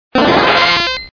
Cri de Stari dans Pokémon Diamant et Perle.